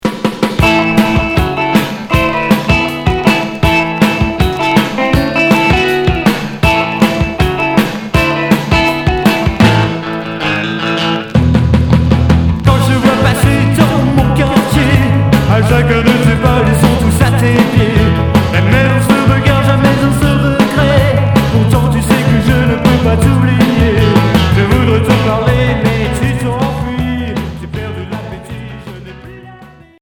Rockabilly Unique 45t retour à l'accueil